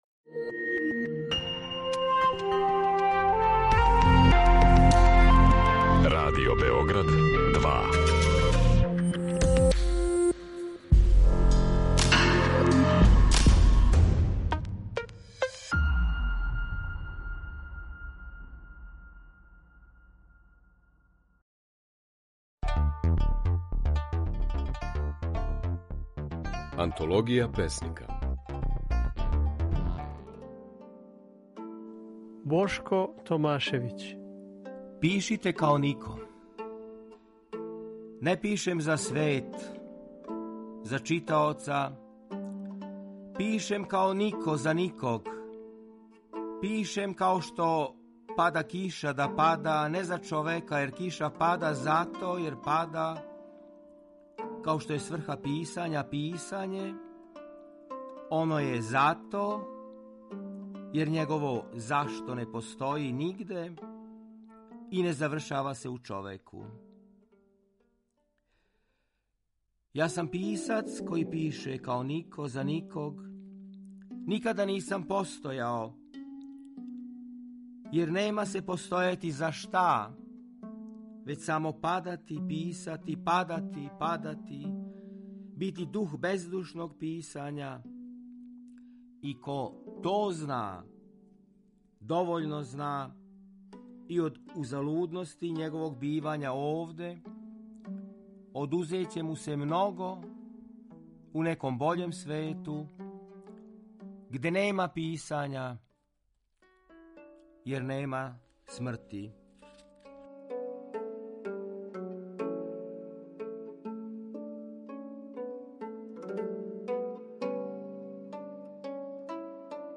Можете чути како своје стихове говори песник, романописац, есејистa и књижевни теоретичар
Емитујемо снимке на којима своје стихове говоре наши познати песници